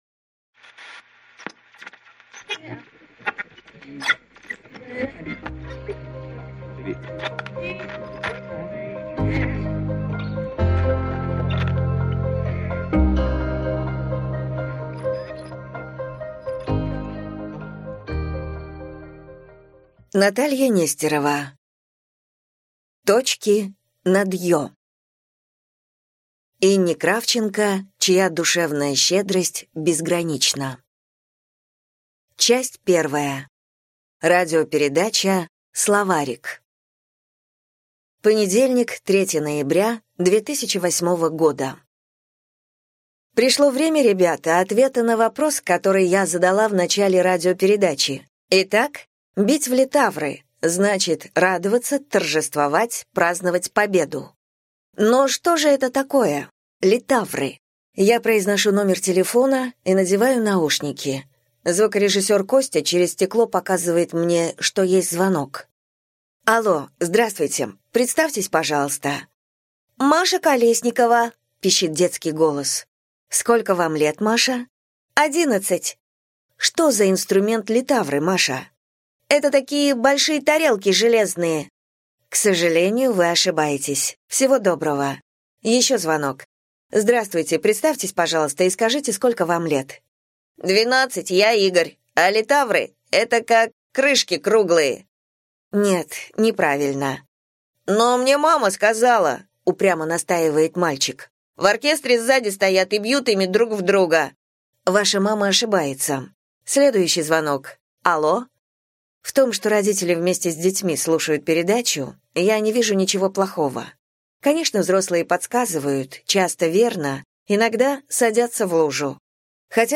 Аудиокнига Точки над «Ё» | Библиотека аудиокниг